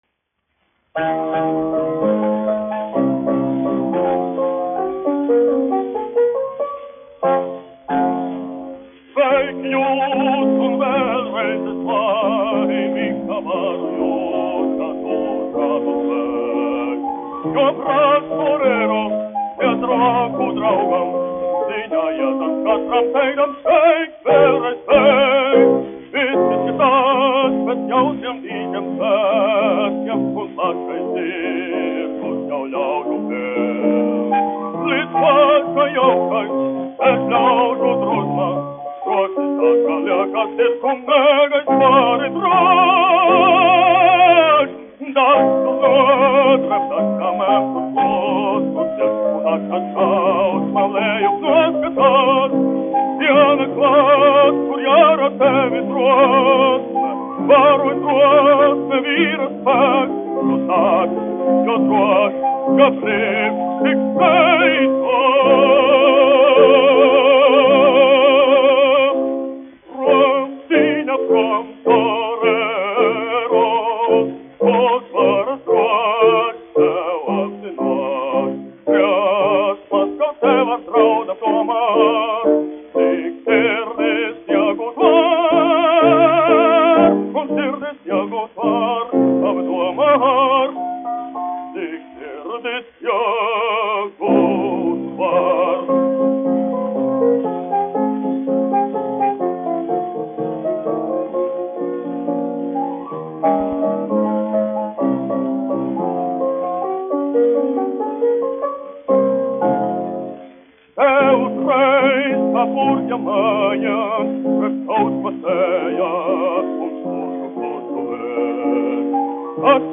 Kaktiņš, Ādolfs, 1885-1965, dziedātājs
1 skpl. : analogs, 78 apgr/min, mono ; 25 cm
Operas--Fragmenti, aranžēti
Latvijas vēsturiskie šellaka skaņuplašu ieraksti (Kolekcija)